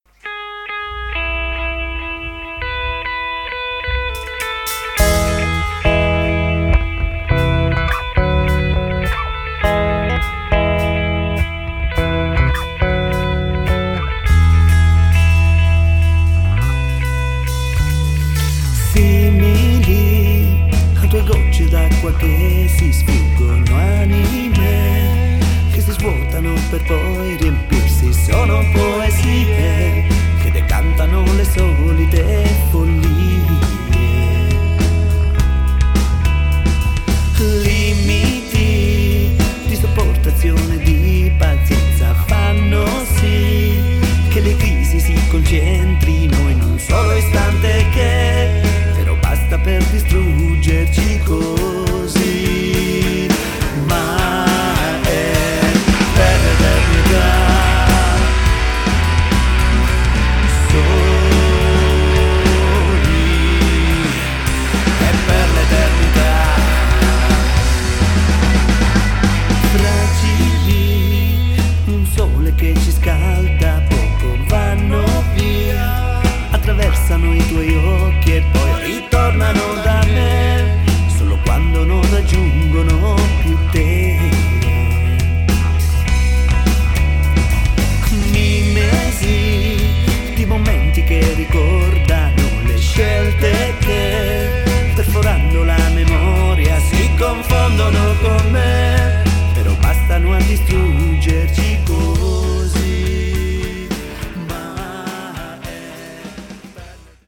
Genere: Rock.